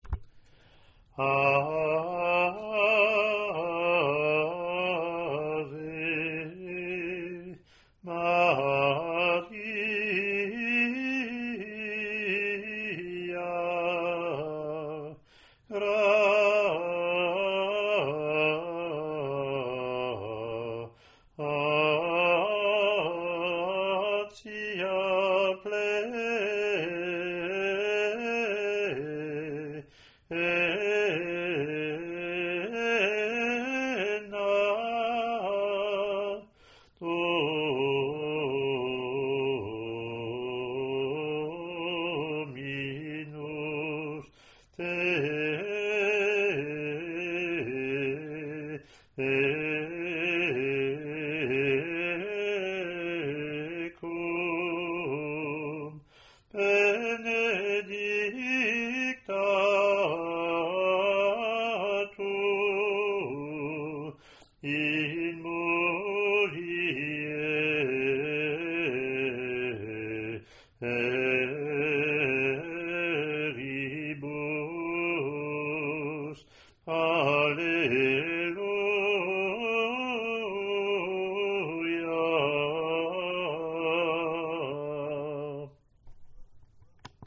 Click to hear Offertory : English antiphon – English verse , then
Latin antiphon